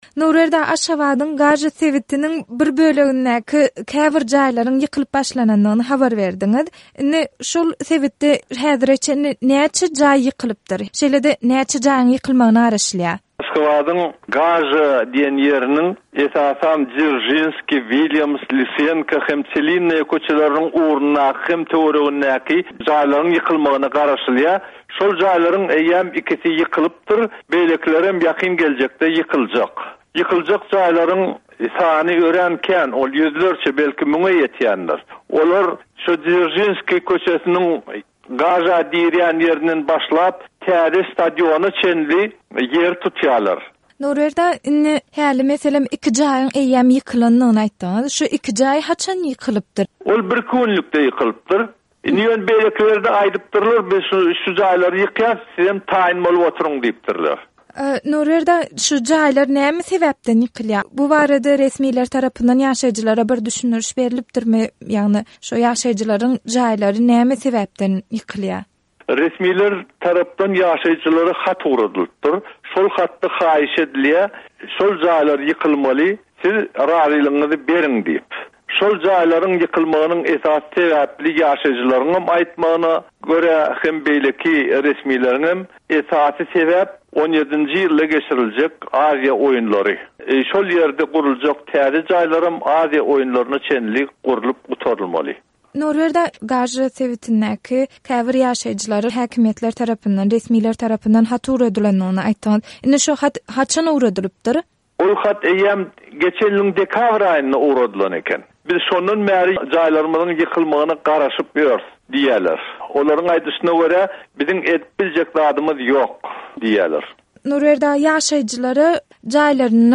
Azatlyk Radiosy Gaža sebitindäki bu jaý ýykyşlyk bilen gyzyklanyp, Aşgabatda ýaşaýan graždan aktiwisti